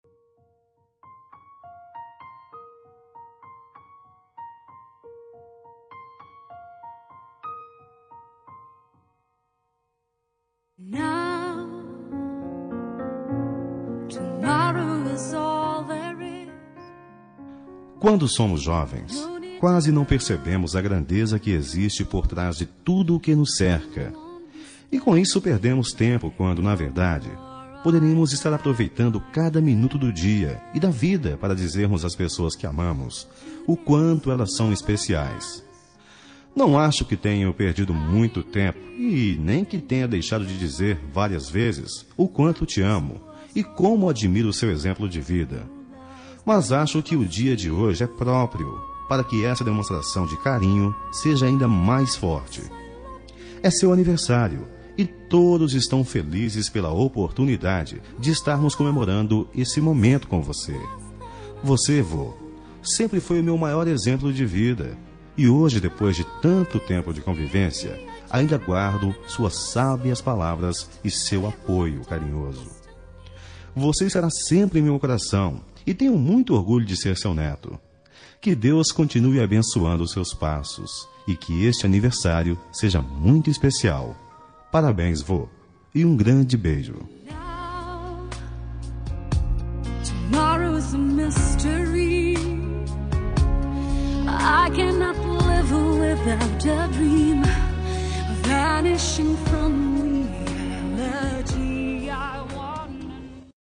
Aniversário de Avô – Voz Masculina – Cód: 2100